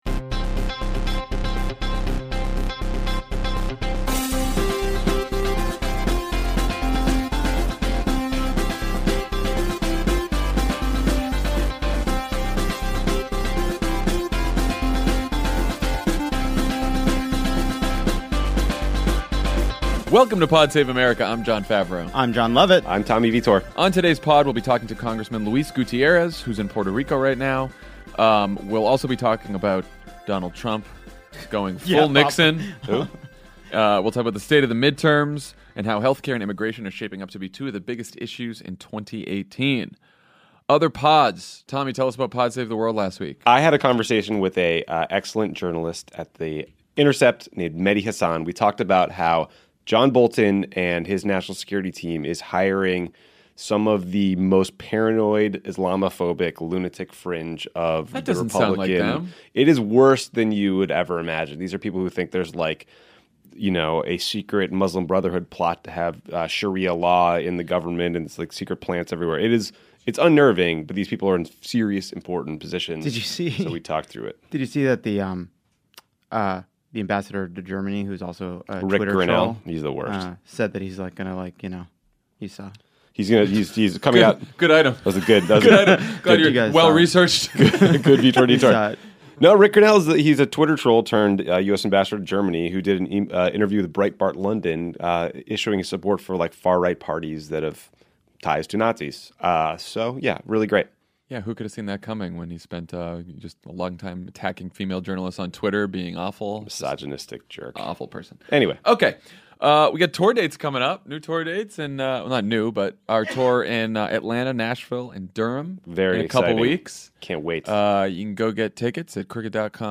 Trump and his legal team argue that the president is above the law, and voters say that health care and immigration are two of the most important issues in the very close 2018 midterms. Then, Rep. Luis Gutierrez calls from Puerto Rico to talk to Jon Favreau about the devastating aftermath of Hurricane Maria.